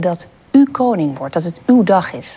Hoort u verschil tussen u en uw?
Beluister wat Marielle Tweebeeke zei tijdens ‘t interview met Prins Willem Alexander en prinses Máxima, op 17 april 2013:
‘t Verschil is blijkbaar niet of niet altijd te horen.